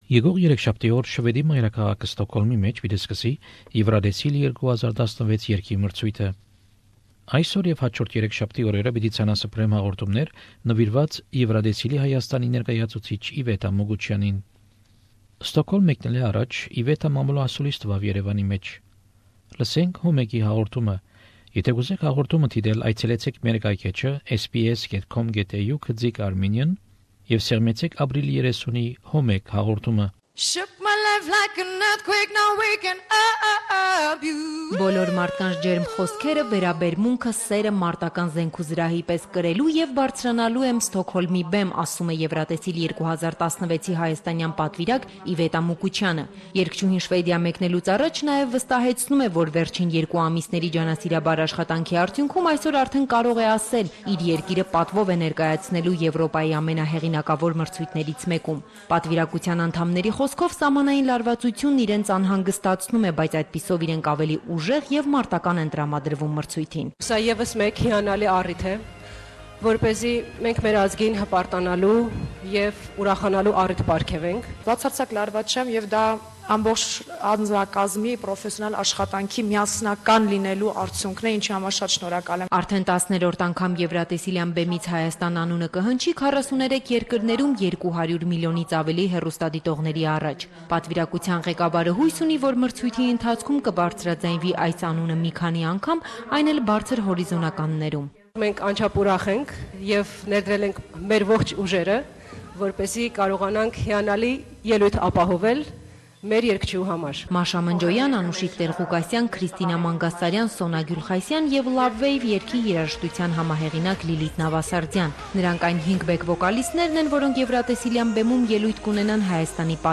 Iveta Mukuchyan, meets with journalists in Stockholm after her first rehearsal for Eurovision song contest.